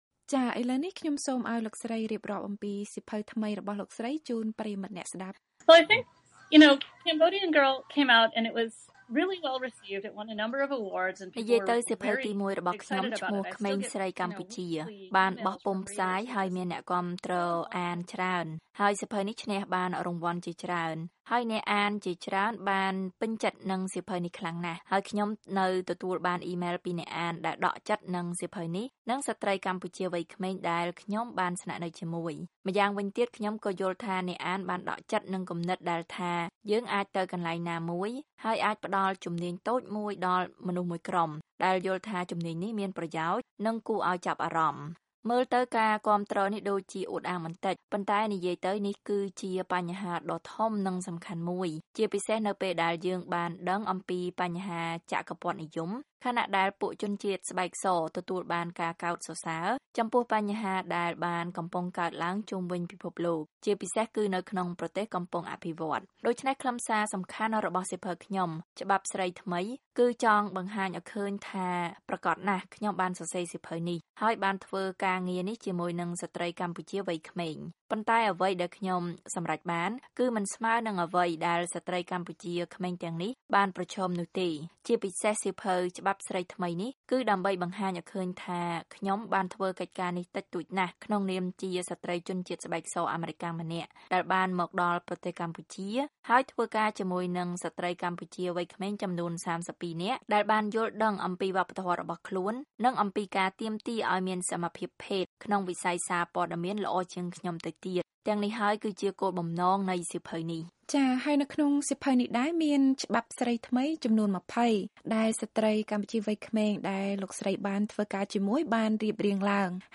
បទសម្ភាសជាមួយស្រ្តីសិល្បករ និងអ្នកនិពន្ធអាមេរិកាំងនិយាយអំពីស្ត្រីកម្ពុជាកំពុងលេចធ្លោ (ភាគ២)